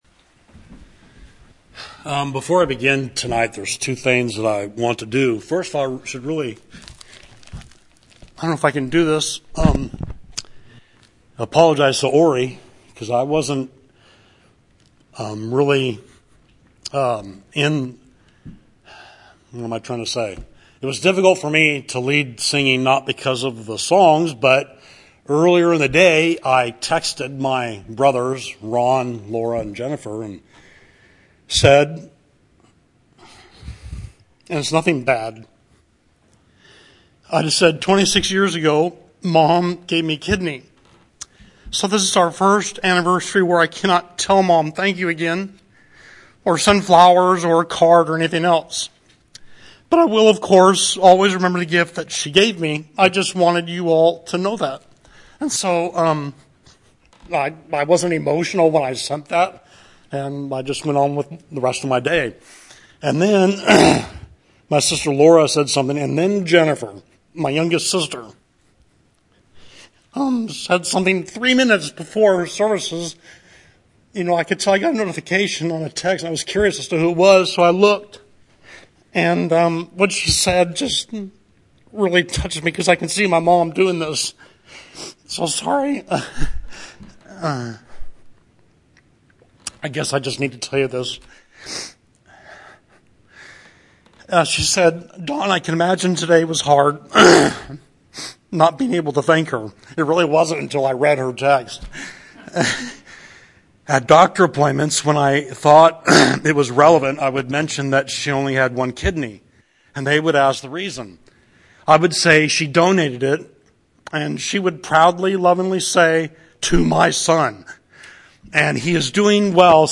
March 24, 2024: PM Worship and Sermon – “Sage Advice”